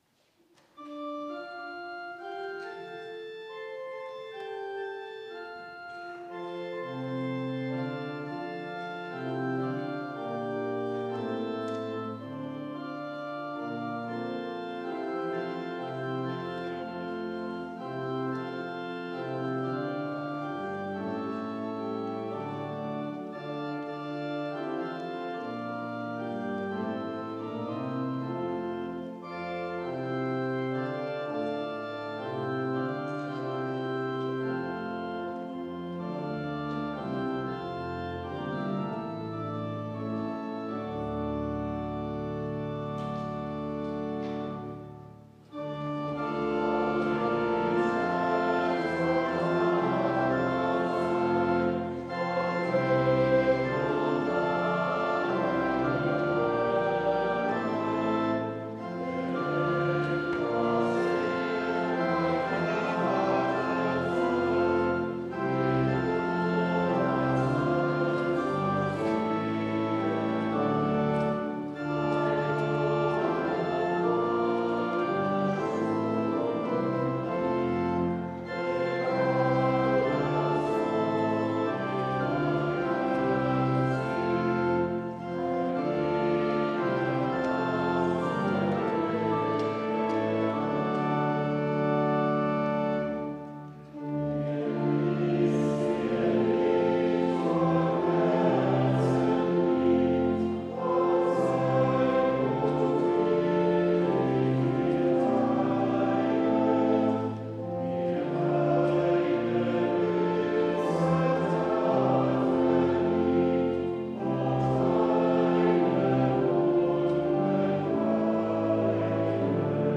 O gläubig Herz, Gott dankbar sei... (LG 388,1-5) Evangelisch-Lutherische St. Johannesgemeinde Zwickau-Planitz
Audiomitschnitt unseres Gottesdienstes am 8. Sonntag nach Trinitatis 2023